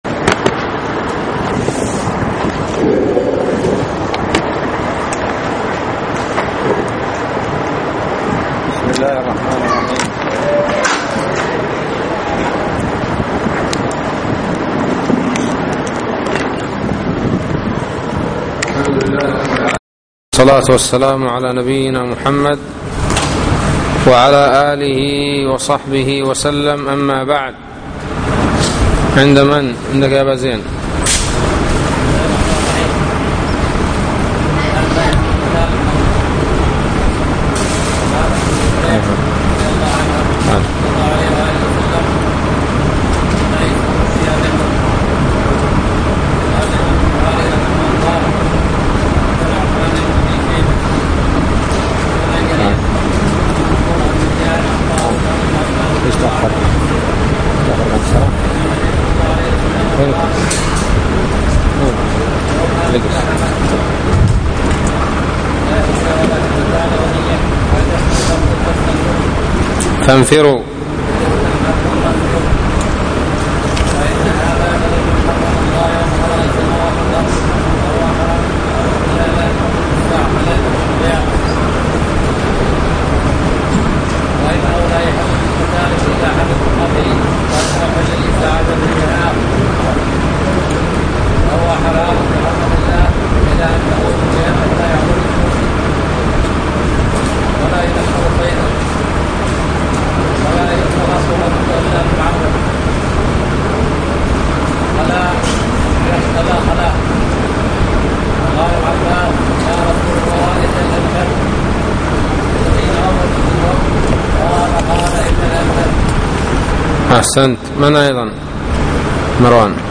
الدرس الثامن : باب الحجامة للمحرم